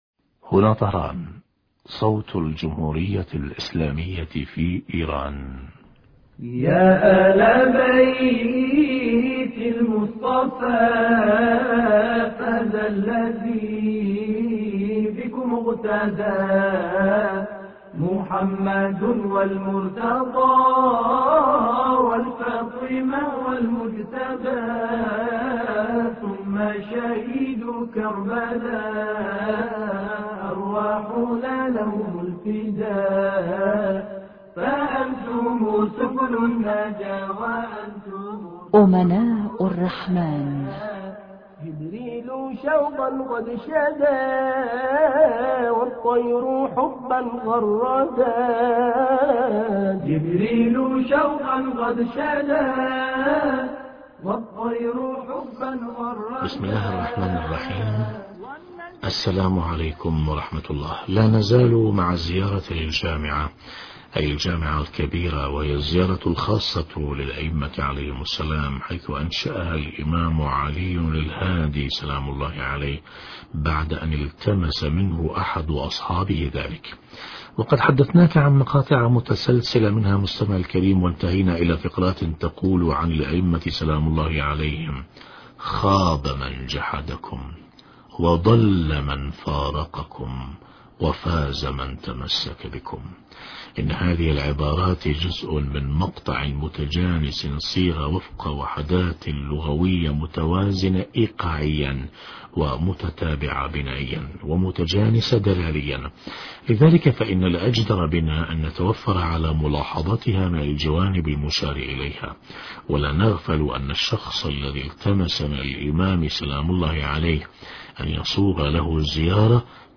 بيان آثار الابتعاد عن ائمة الحق (عليه السلام) حوار